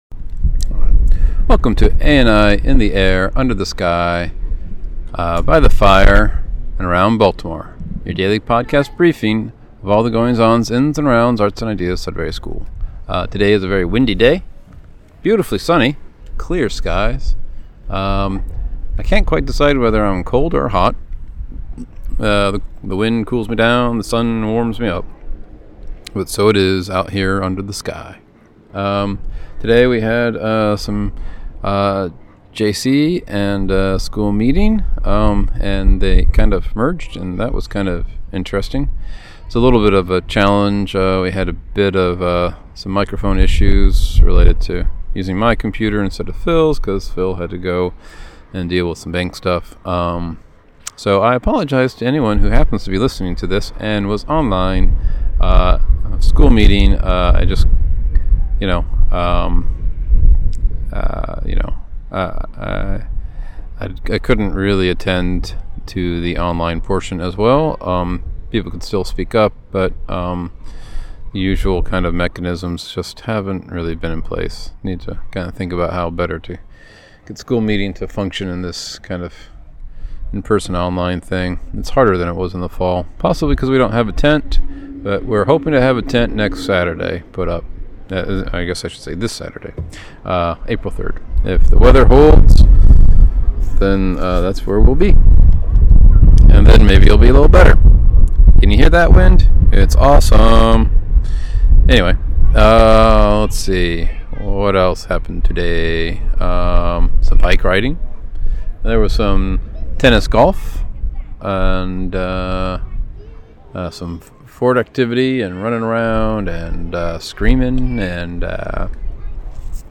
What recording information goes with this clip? Windy sunny day.